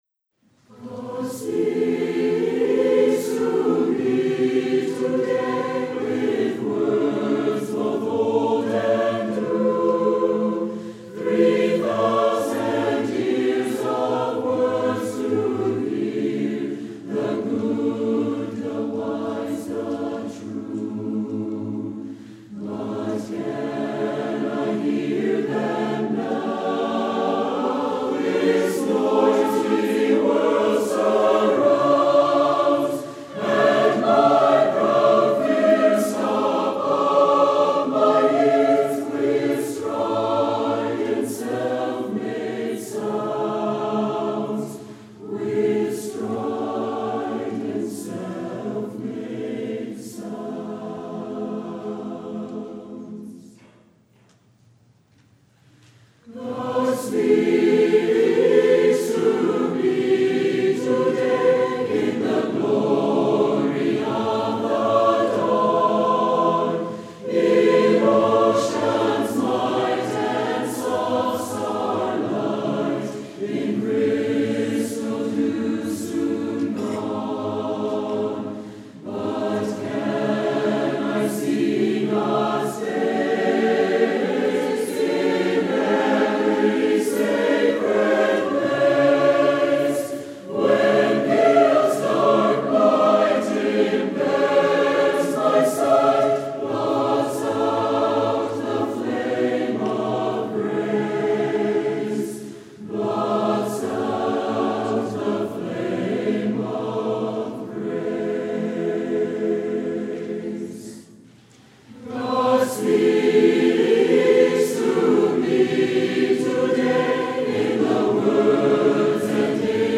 Hymns (free for congregational use)Voicing LengthPrice